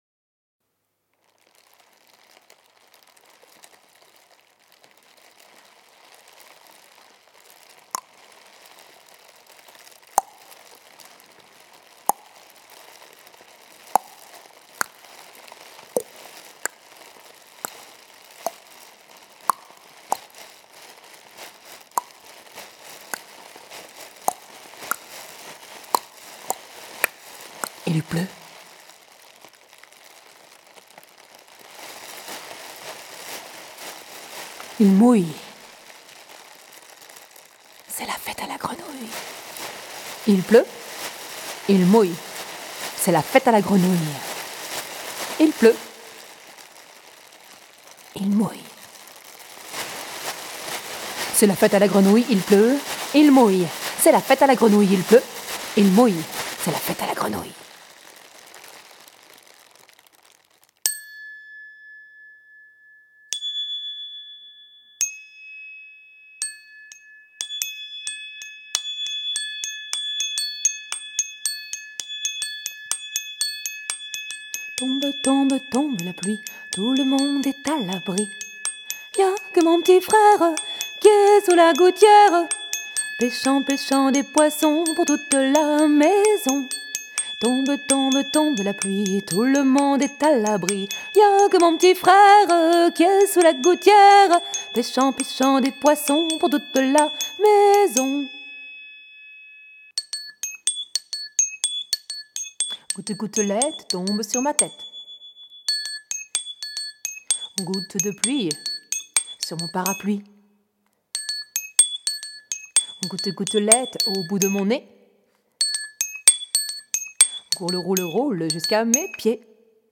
Contes et comptines pour les tout-petits
Ce spectacle  est construit autour de quelques instruments – Concertina, percussions, flûte…
Ils accompagnent et rythment la voix tantôt parlée, tantôt chantée.